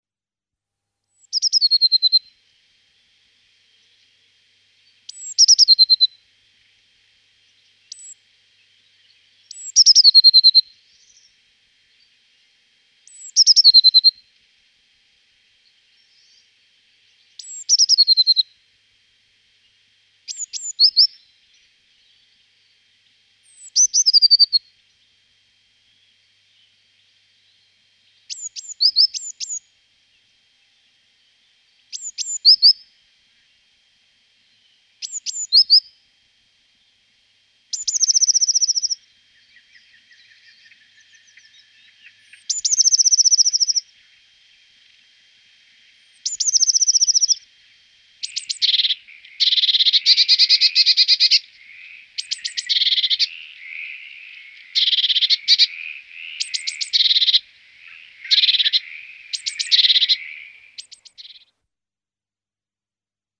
Chant :
Mésange bleue
La Mésange bleue zinzinule. Le cri de la mésange bleue est un petit cri aigu "tsi-tsi" . Son chant reprend les éléments du cri en les prolongeant. Sur le continent, il comporte presque toujours un trille très aigu.